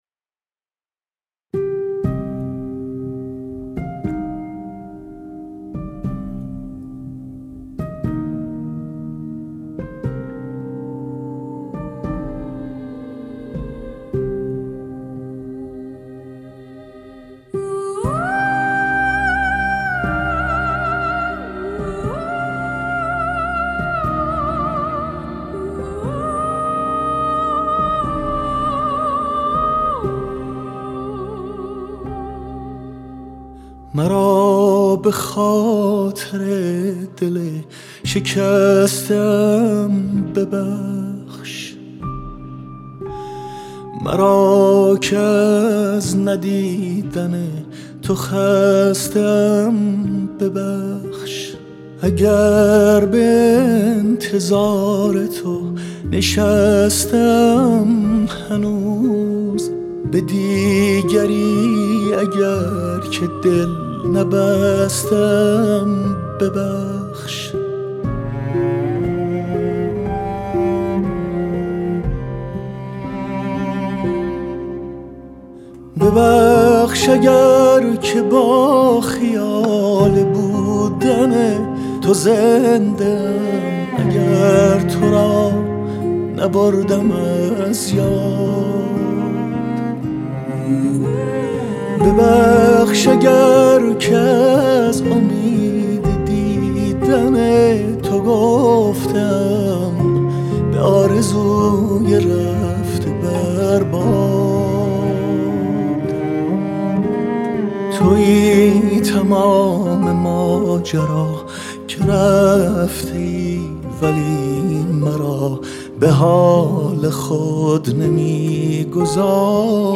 اصیل ایرانی
موسیقی سنتی